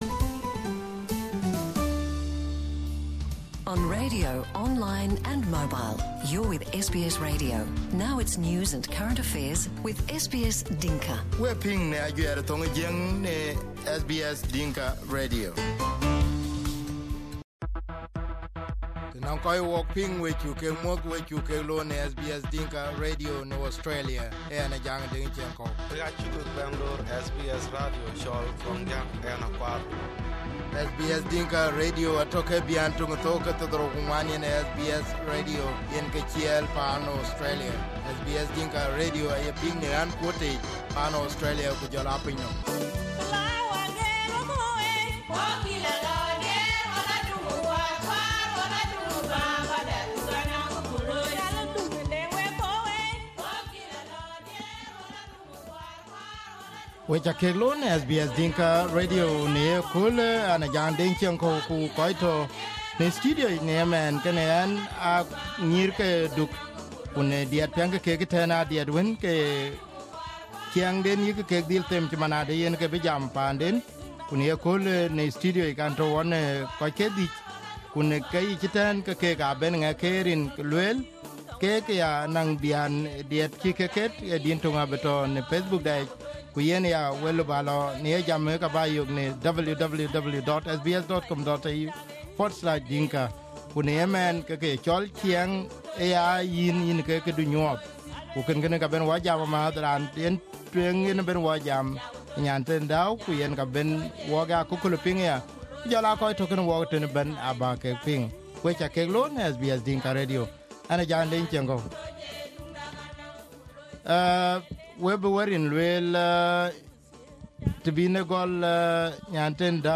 They are using music as the way to appeal to people across Australia and their target is to buy an ambulance for the sick. Five members of the group came to SBS Dinka Radio studio for this interview.